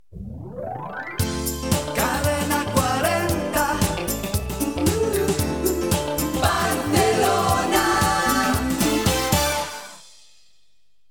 Indicatiu "Funky" de l'emissora
FM